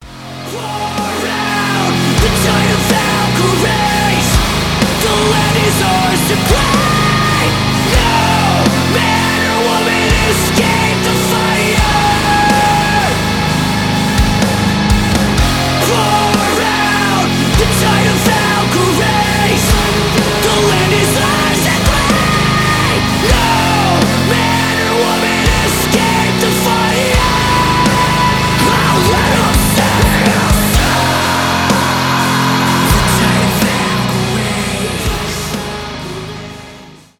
progressive metal , metalcore , мощные